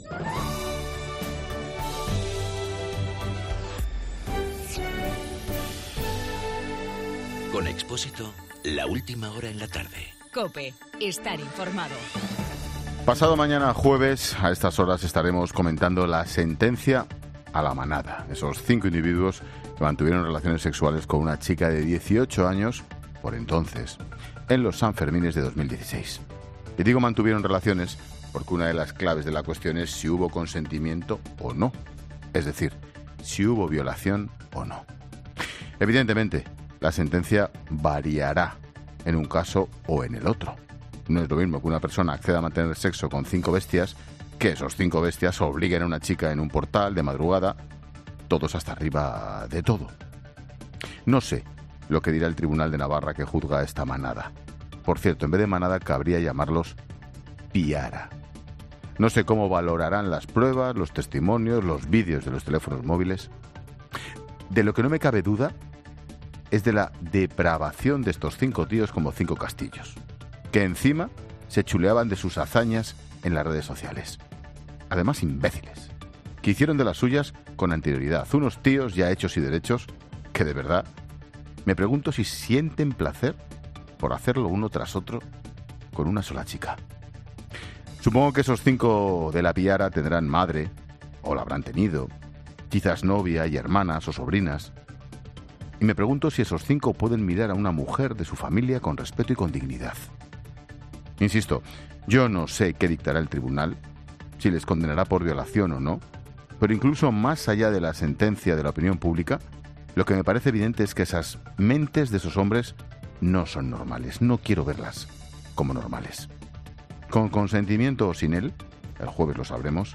Monólogo de Expósito
El comentario de Ángel Expósito antes de la sentecia del juicio por violación a La Manada.